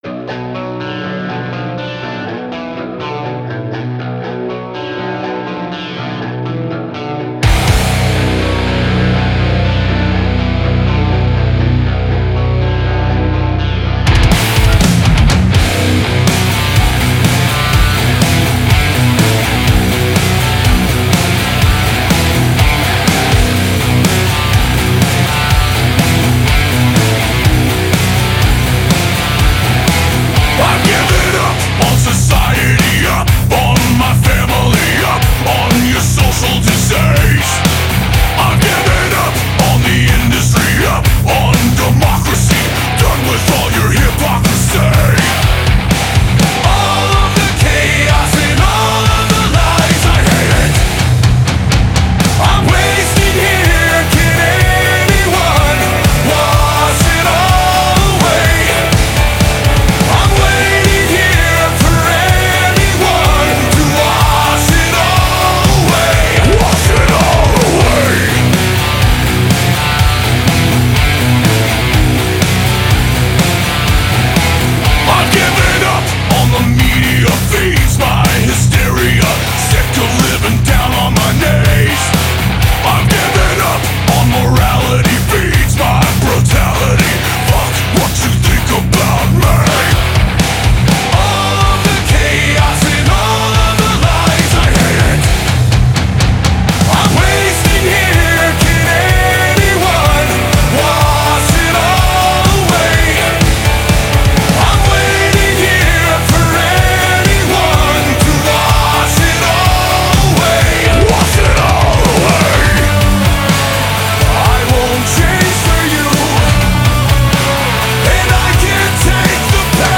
Рок музыка
зарубежный рок